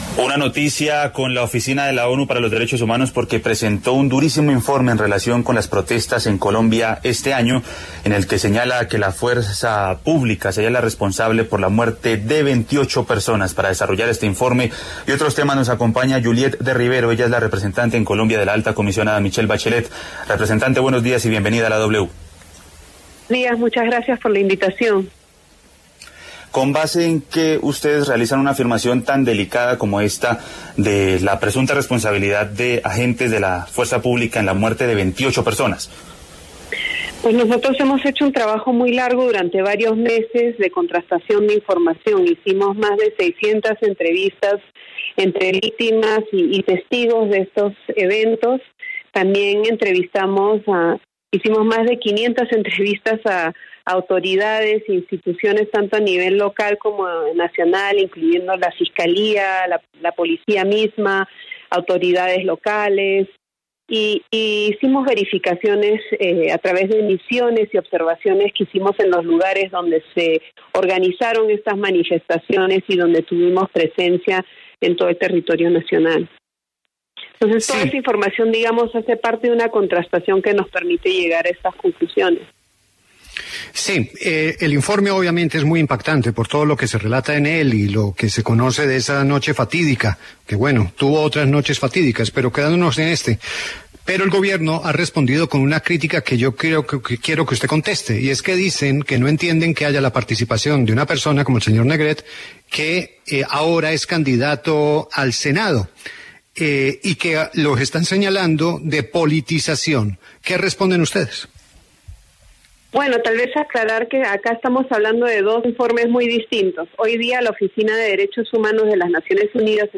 Además, en entrevista con La W, la representante Juliette de Rivero aclaró que no tuvieron participación alguna en el informe presentado por la Alcaldía de Bogotá el lunes.